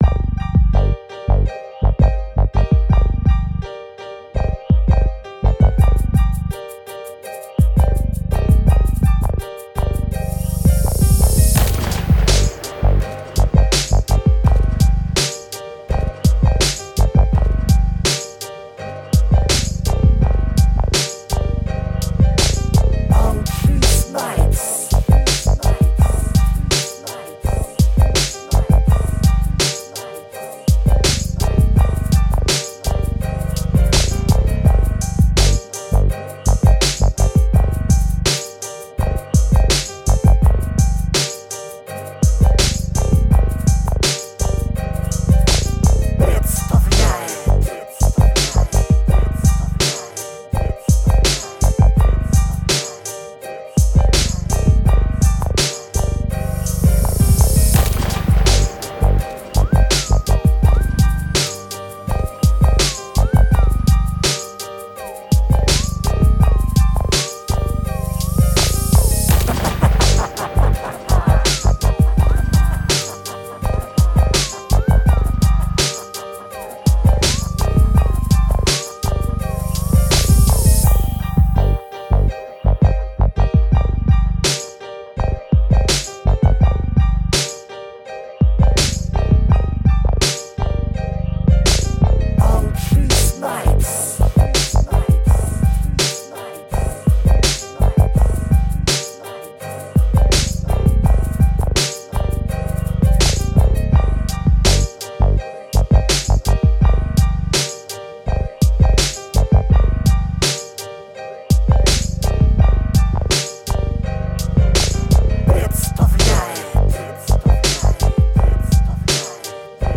Piano G-Funk Beat